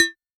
RDM_TapeB_SY1-Perc01.wav